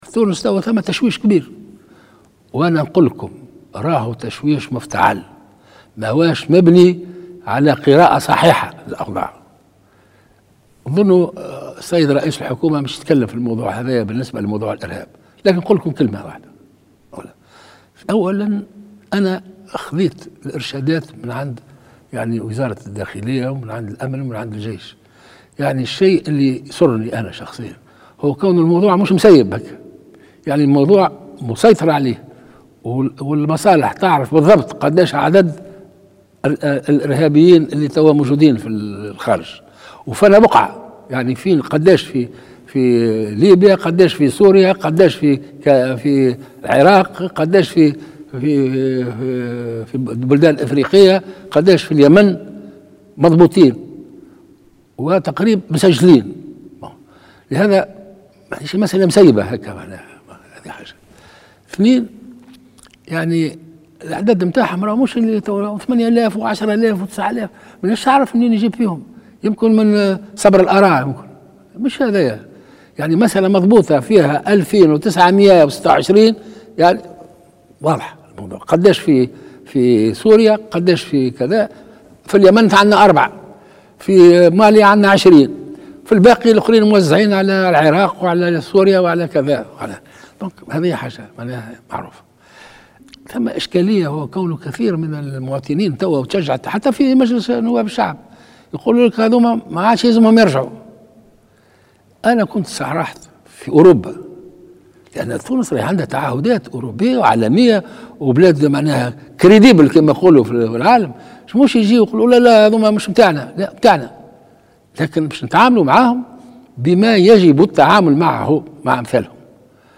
قال رئيس الجمهورية الباجي قايد السبسي في كلمة مساء اليوم السبت بمناسبة حلول السنة الجديدة، إن عدد الارهابيين "التونسيين" خارج تونس يقدر بنحو 2926 ارهابيا، بحسب معطيات دقيقة لدى السلطات التونسية وعلى رأسها وزارة الداخلية.